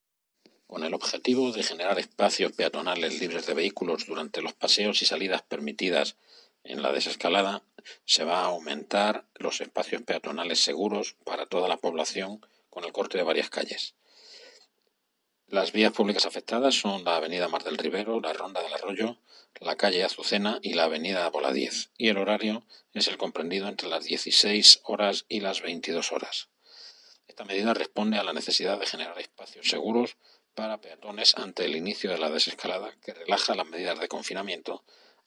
El concejal de Movilidad, Juan José Pérez del Pino, ha explicado que el horario de peatonalización de estas calles será entra las 16:00 horas y las 22:00 horas, de tal forma que todos los ciudadanos que pueden realizar salidas en las condiciones establecidas por las autoridades sanitarias podrán disfrutar de esta medida en los tramos horarios correspondientes a sus edades.